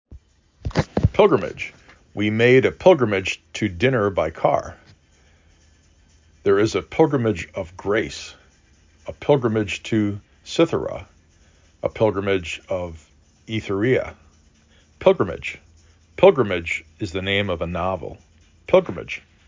p i l · g r ə · m i j
3 Syllables: PIL-grim-age
Stressed Syllable: 1